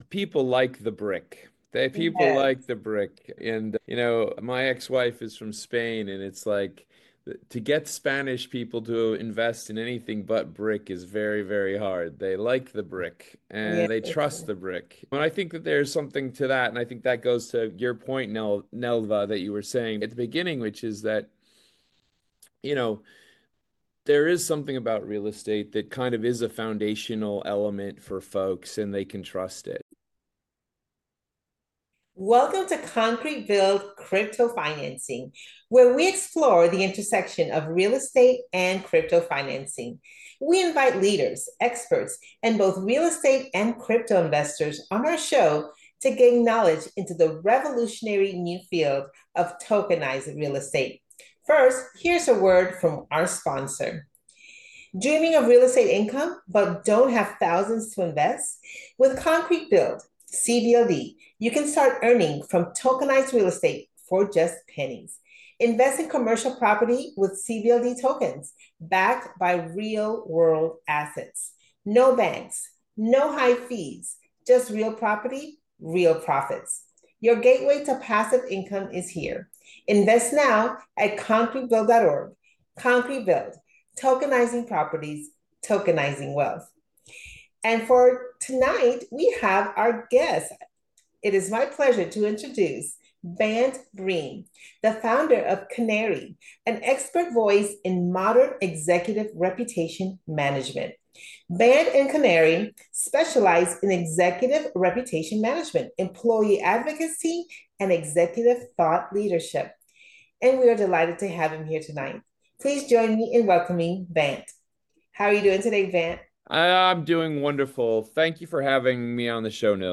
Concrete Build Crypto Financing, is a show that highlights the intersection of Real Estate and Crypto and navigating the two investments The goal is to highlight the revolutionary nature of Crypto Financing of Real Estate and Tokenization of Commercial Property. Your story matters and will resonate with many who need the knowledge The recording environment is casual and comfortable - I want guests to feel at ease and always start with a meditation before the interview begins. Authenticity is valued over polish, so guests are encouraged to speak honestly without filtering their thoughts.